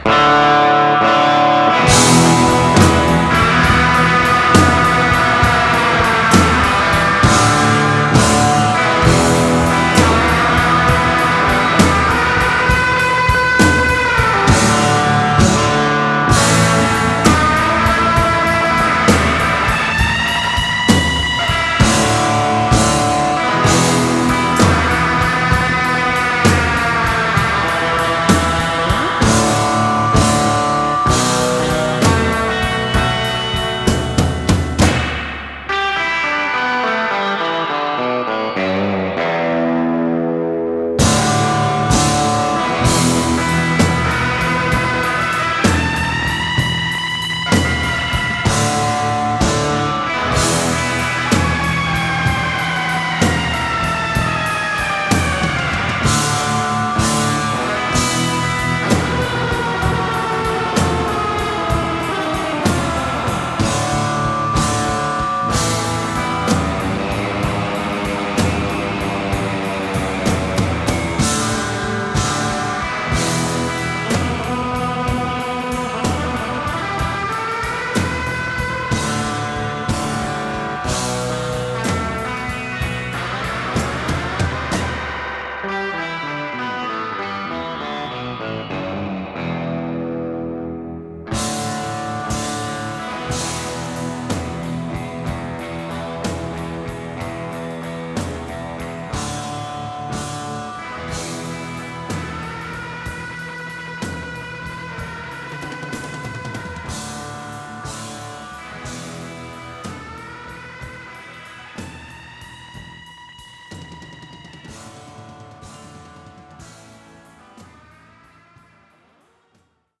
spitfire, snarling version